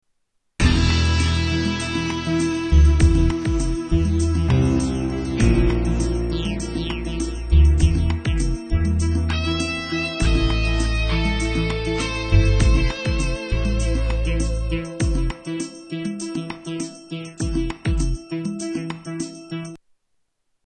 Tags: Sound effects Espionage music Espionage Stealth Music